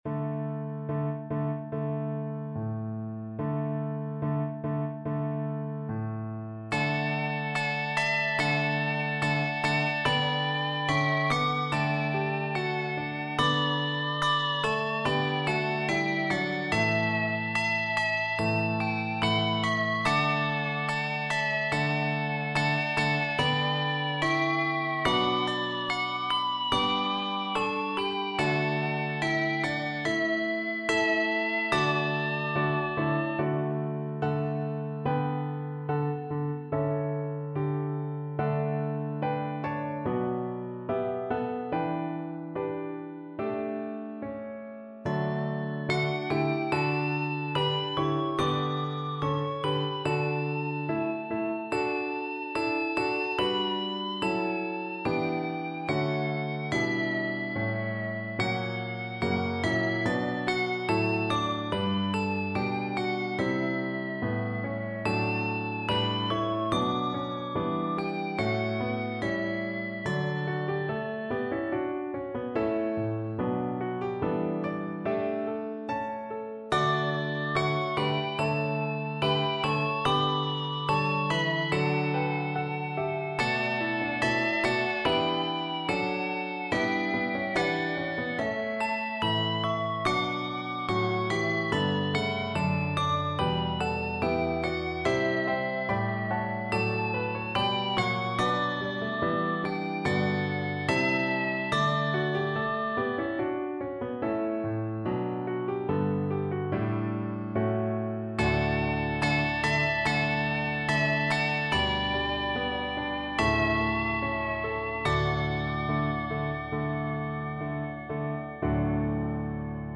18th-century hymn tune
Hymn Tune